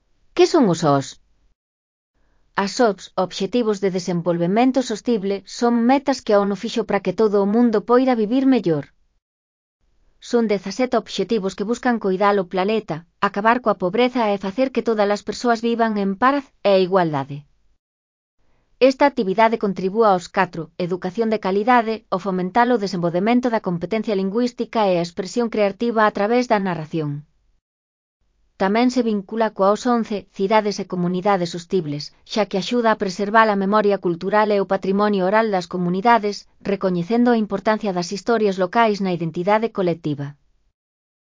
Elaboración propia (proxecto cREAgal) con apoio de IA voz sintética xerada co modelo Celtia.